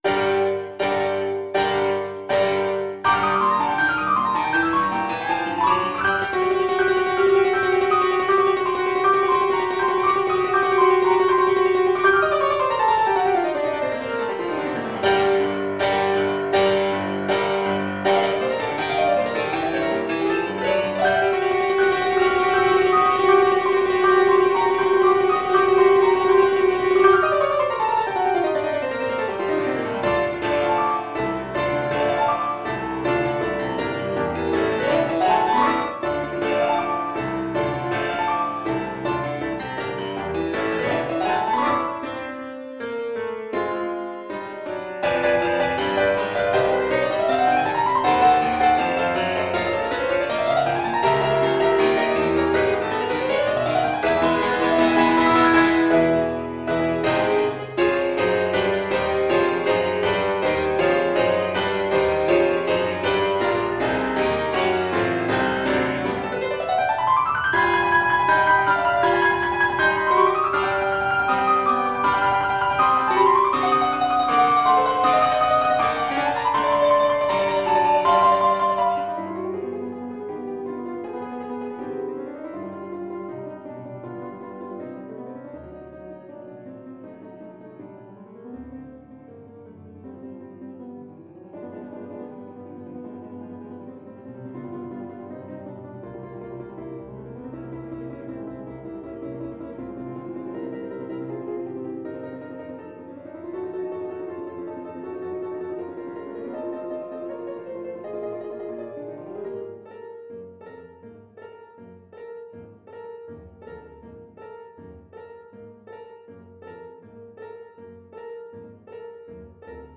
Allegro agitato (=80) (View more music marked Allegro)
Classical (View more Classical Flute Music)